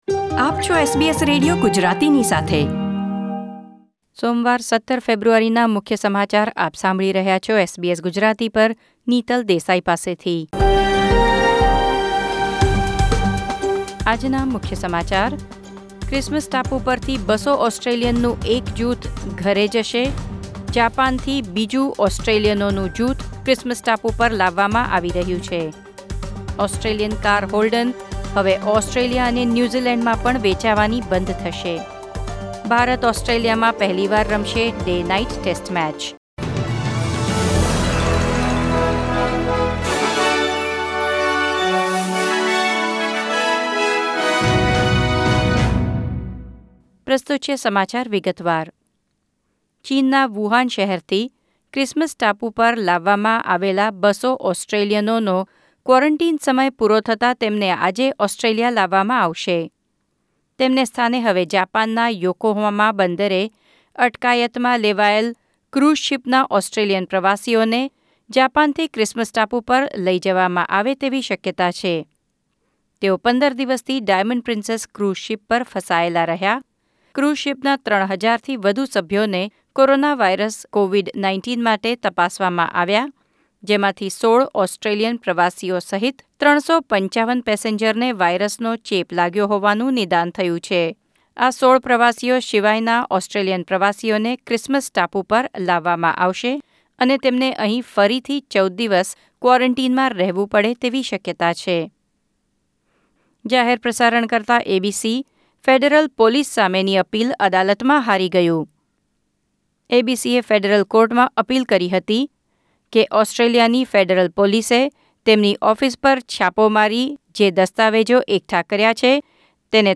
SBS Gujarati News Bulletin 17 February 2020